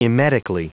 Prononciation du mot emetically en anglais (fichier audio)
Prononciation du mot : emetically